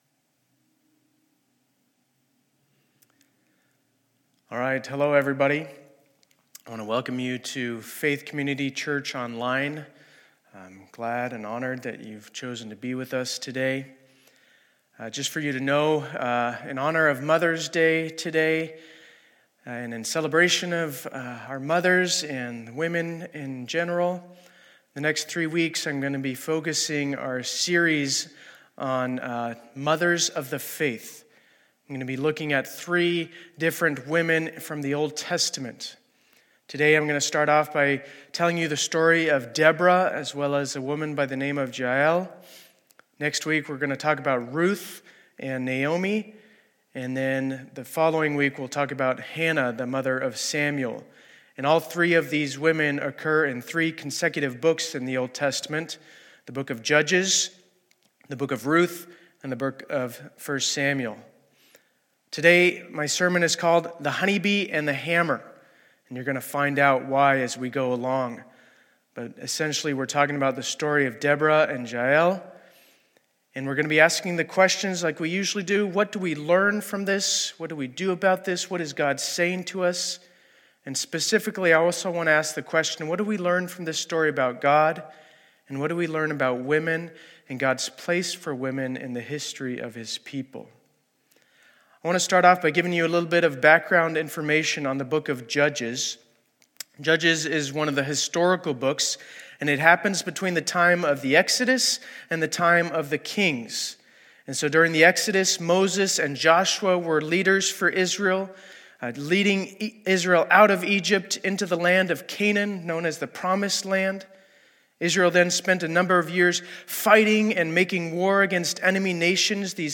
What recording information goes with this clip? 2020-05-10 Sunday Service